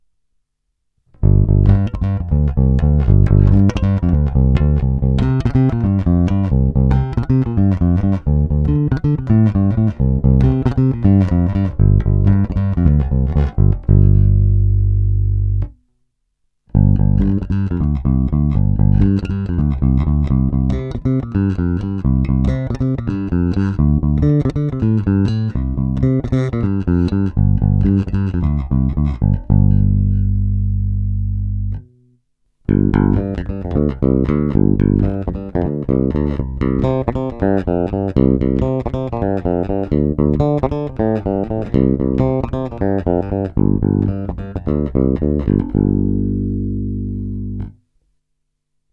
Následující nahrávky jsou provedeny rovnou do zvukové karty a dále ponechány bez jakýchkoli úprav, kromě normalizace samozřejmě.
Ukázka ve stejném pořadí snímačů jako výše